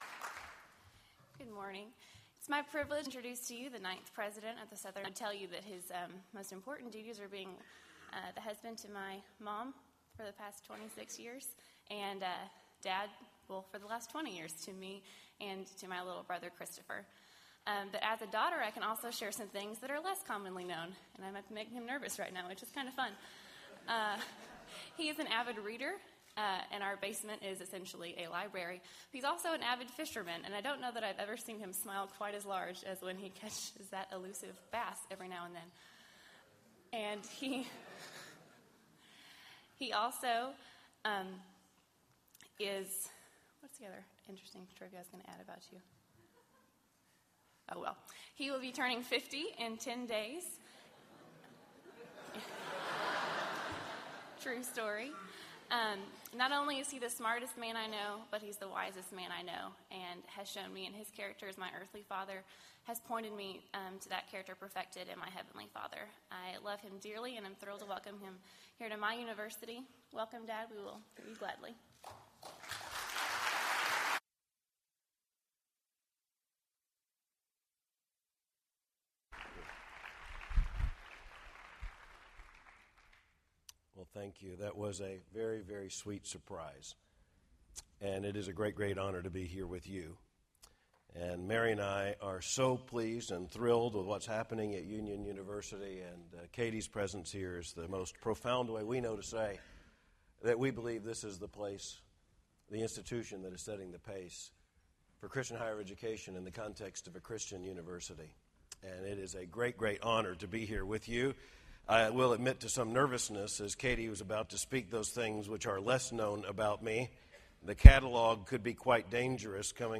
Future of Denominationalism Conference: R. Albert Mohler, Jr
R. Albert Mohler, Jr , President, Southern Baptist Theological Seminary, Louisville, KY Address: Southern Baptists, Evangelicals, and the Future of Denominationalism Recording Date: Oct 9, 2009, 10:00 a.m. Length: 37:54 Format(s): MP3 ;